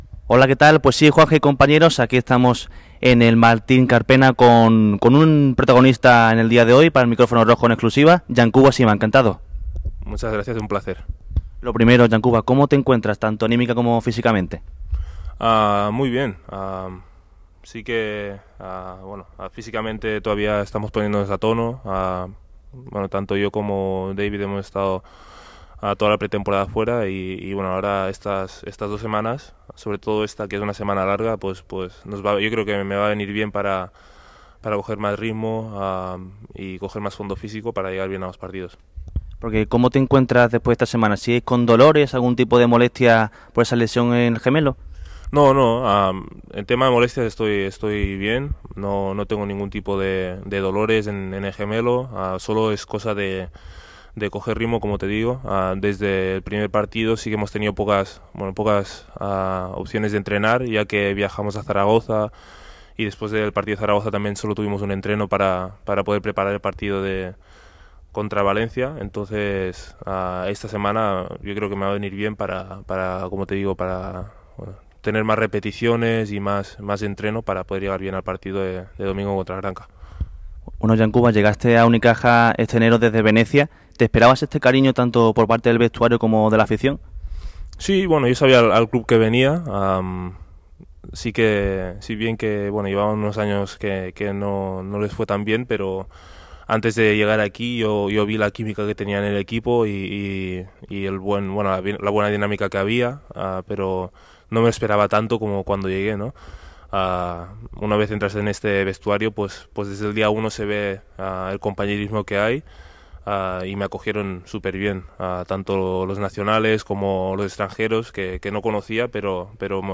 Entrevista-Yankuba-Sima.mp3